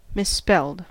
Ääntäminen
IPA : /mɪsˈspelt/